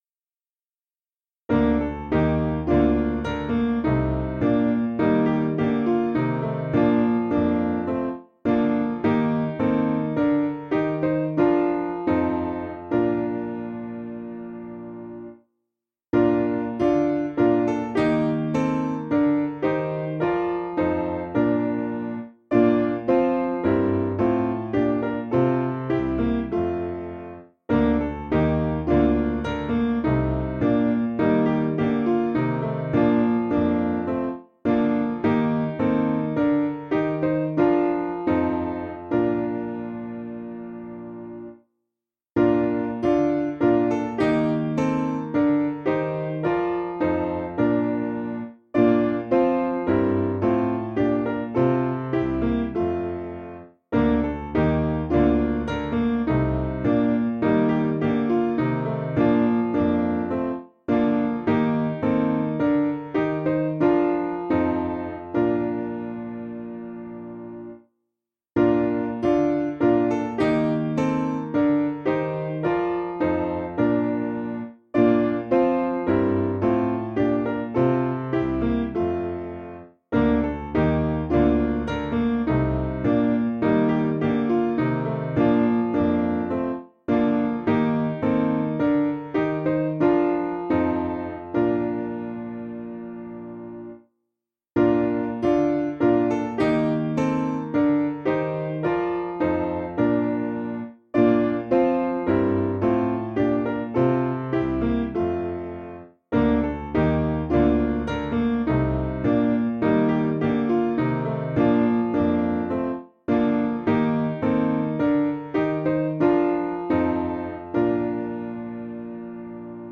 Simple Piano
(CM)   5/Bb 467.5kb